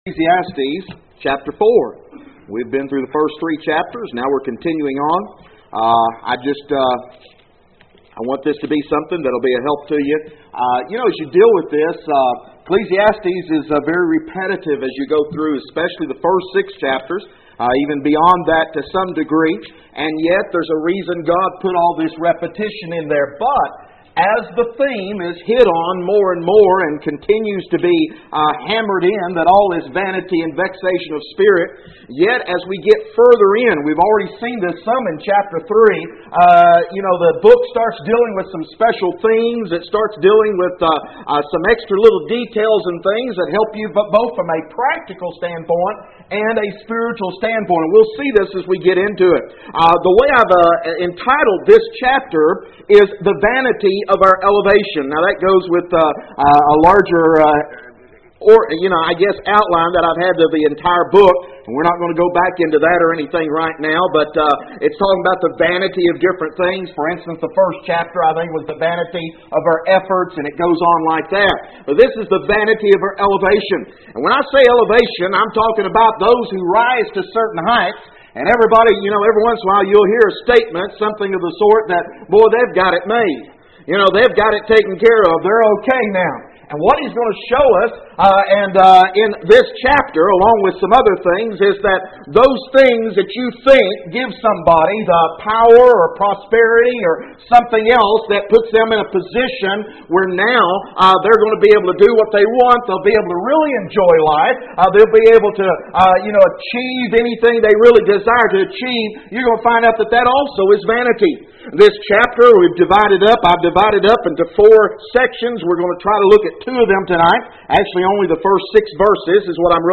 Text: Ecclesiastes 4:1-6 No Outline at this point. For More Information: More Audio Sermons More Sermon Outlines Join the Learn the Bible mailing list Email: Send Page To a Friend